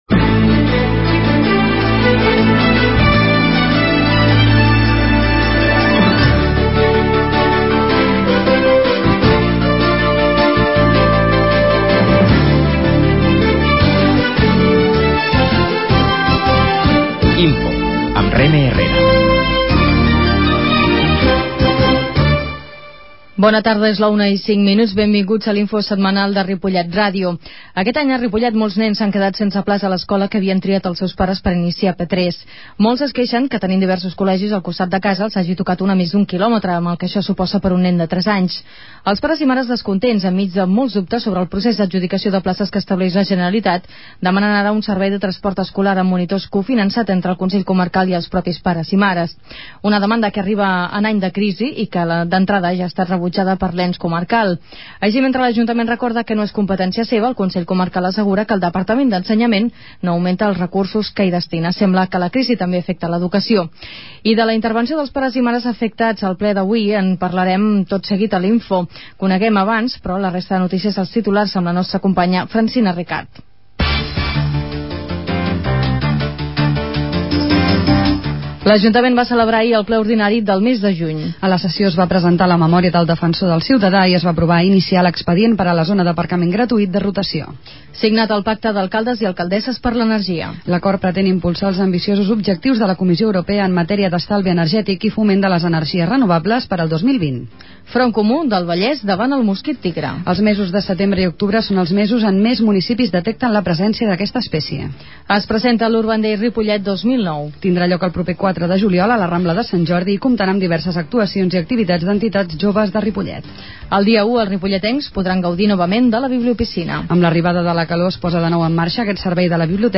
Comunicació INFO de la setmana: 26 de juny de 2009 -Comunicació- 27/06/2009 Escolteu en directe per la r�dio o la xarxa el resum de not�cies de Ripollet R�dio (91.3 FM), que s'emet en directe a les 13 hores.
La qualitat de so ha estat redu�da per tal d'agilitzar la seva desc�rrega.